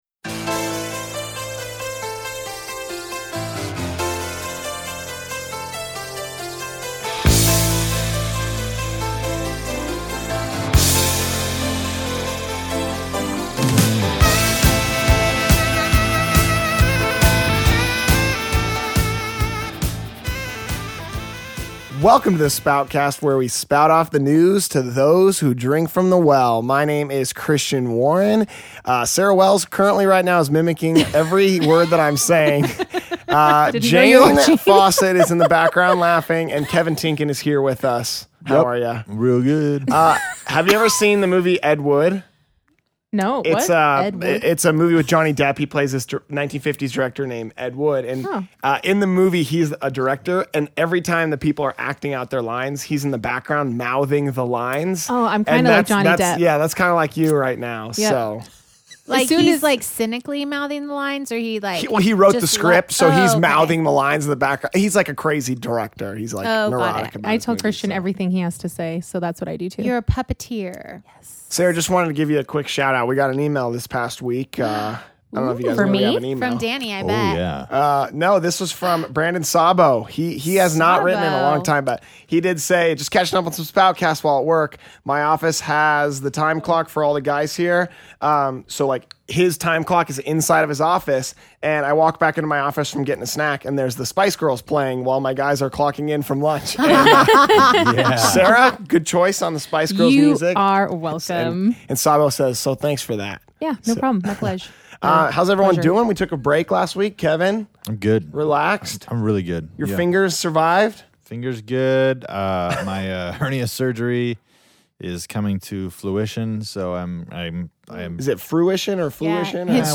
This week's interview is with the 4 of us. The Spoutcast Crew talks about all the new happenings at the Well in the Fall. The Crew also discusses their thoughts on the 1 Corinthians series.
This week's music is from various live worship artists.